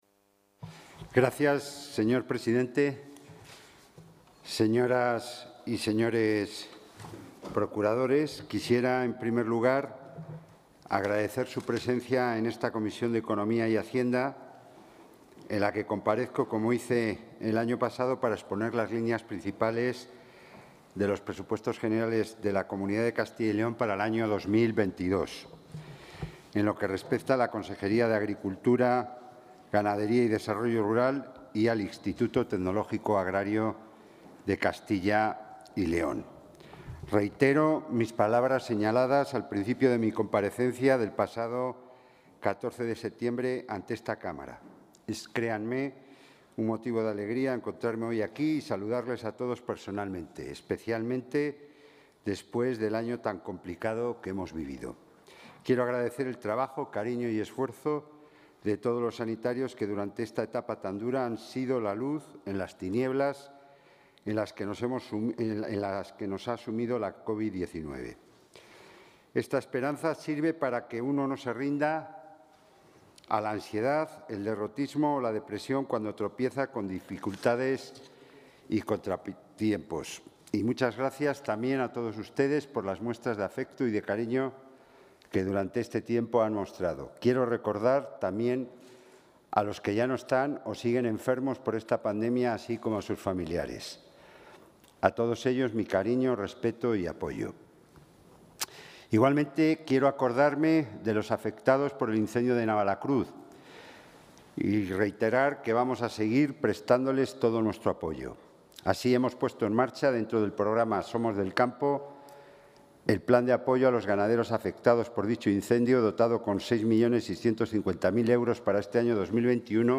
Intervención consejero.